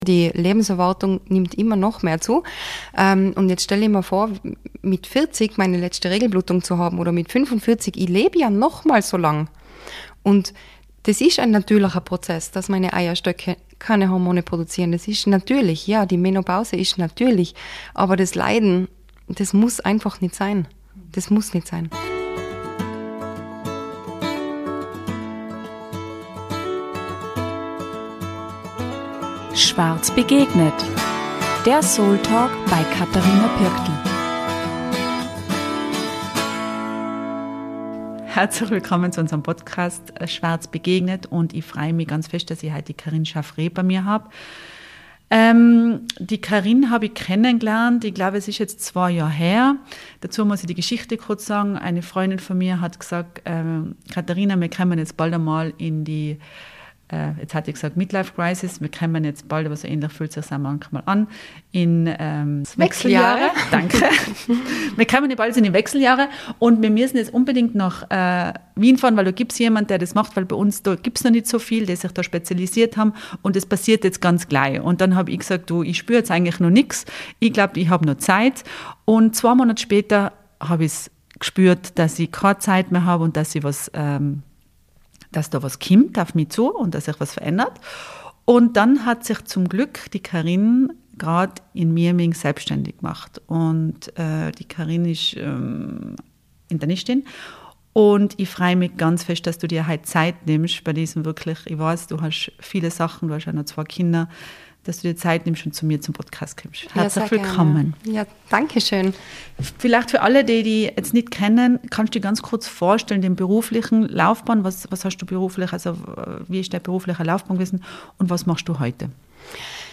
Denn: Die Menopause ist ein natürlicher Prozess, aber das Leiden muss nicht sein. Ein Gespräch über medizinisches Wissen, persönliche Erfahrungen und vor allem über die große Chance, sich selbst neu zu begegnen.